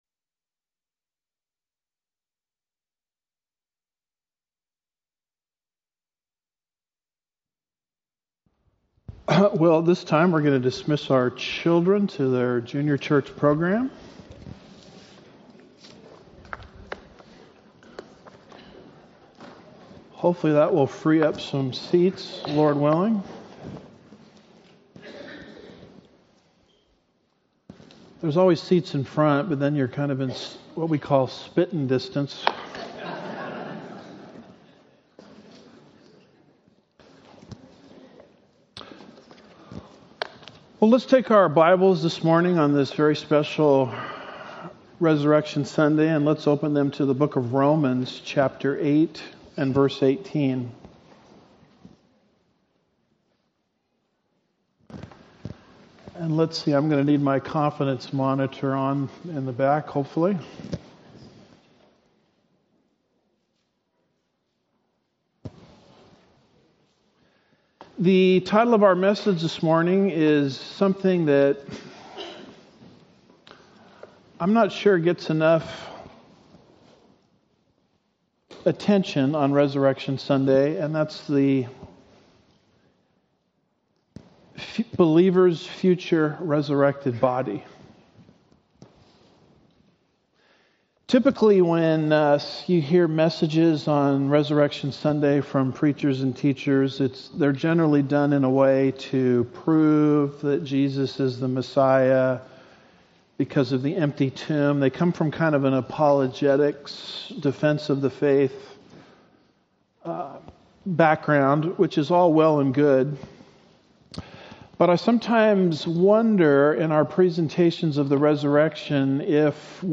Easter Sermons